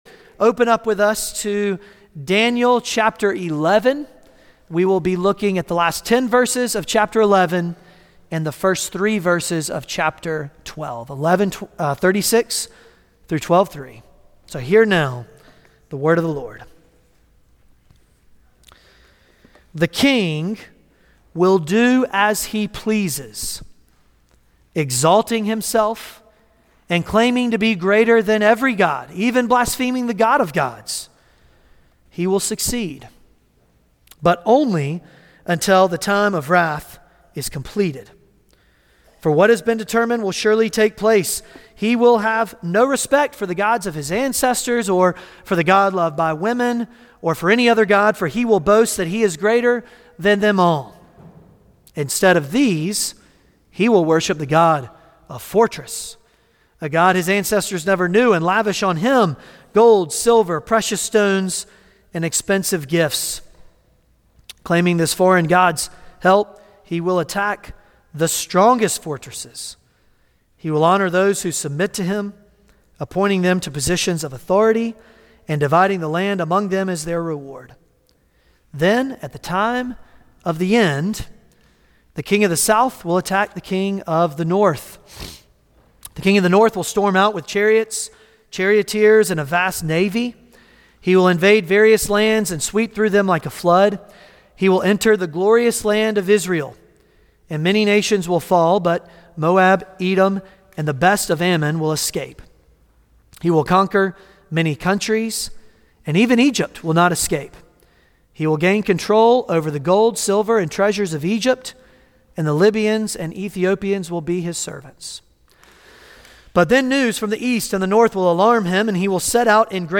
Daniel Passage: Daniel 11:36-12:3 Service Type: Sunday Morning Daniel 11:36-12:3 « Those Who Know The Lord Will Be Strong And Resist How Long?